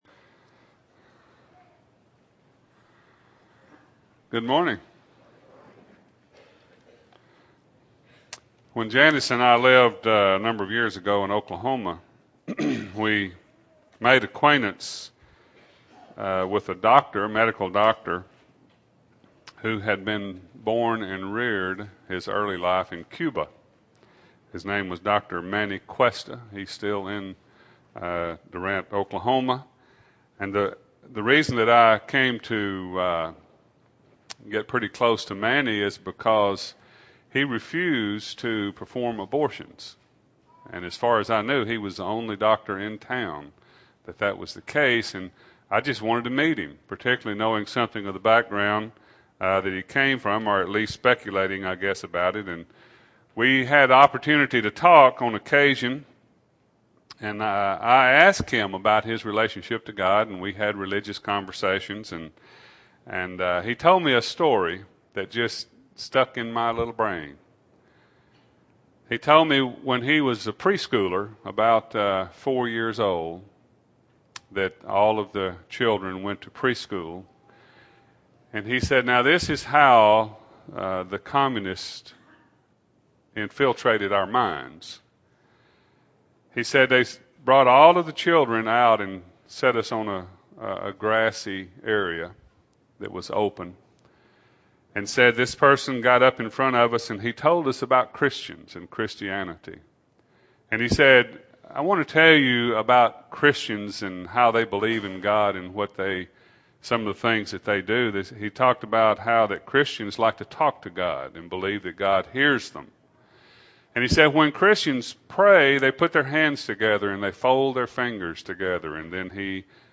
2008-07-13 – Sunday AM Sermon – Bible Lesson Recording